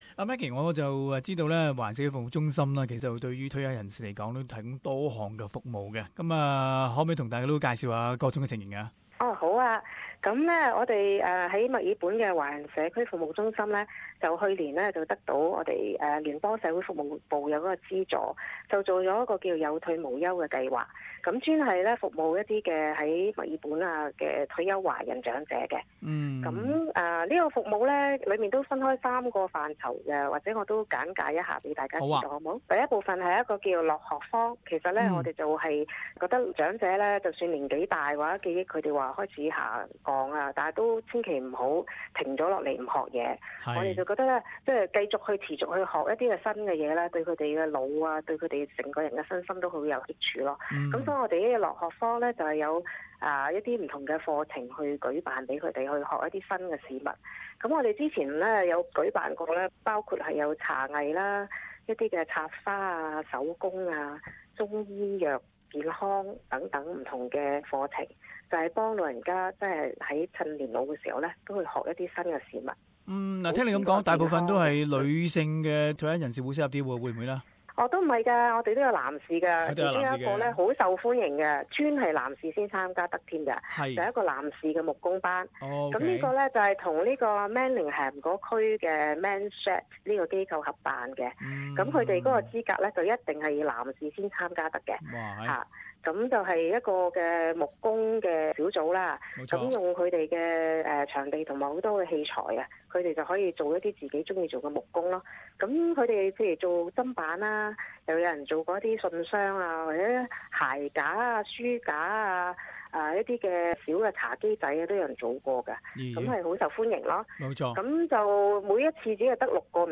【社区专访】『人生锦标奖』－ 维省华人长者自我成就表彰大会